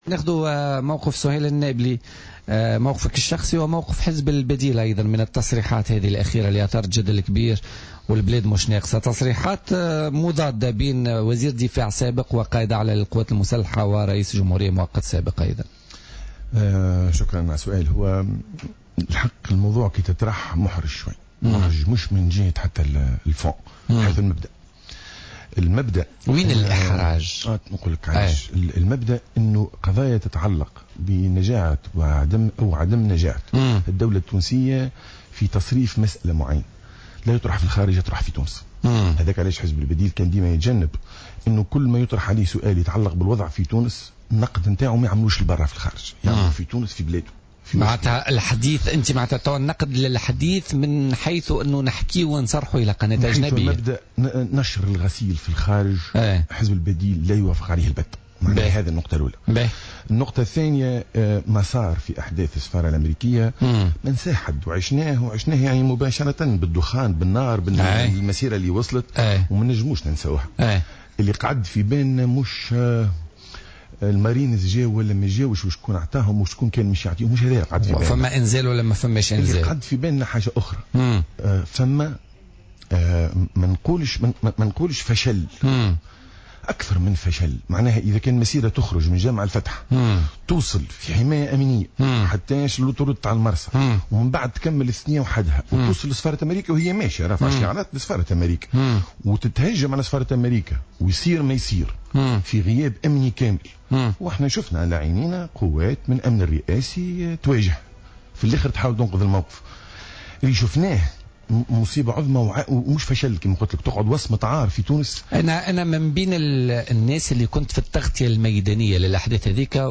خلال استضافته في برنامج "بوليتيكا"